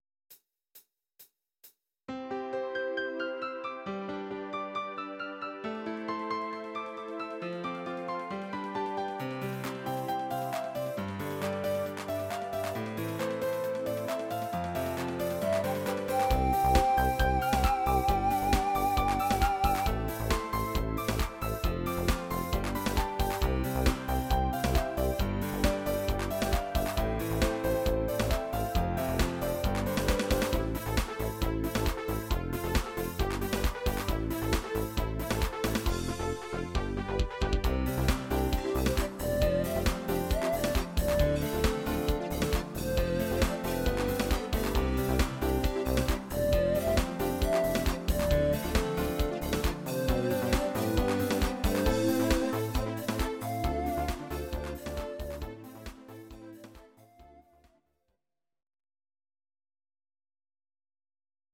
Audio Recordings based on Midi-files
Pop, Dutch, 1990s